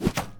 arrow-impact-3.ogg